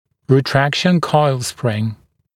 [rɪ’trækʃn kɔɪl sprɪŋ][ри’трэкшн койл сприн]ретракционная витая пружина